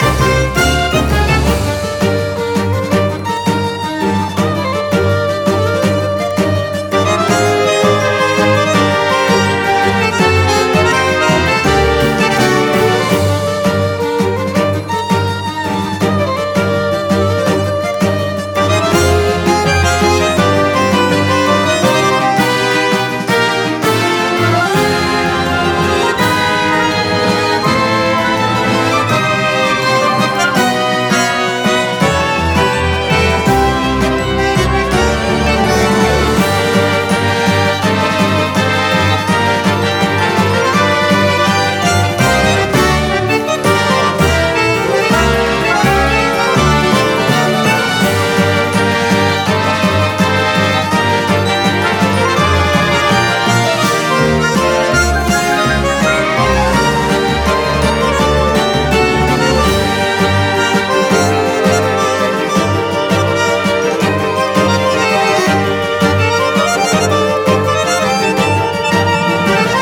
🎻ＢＧＭをご用意しました。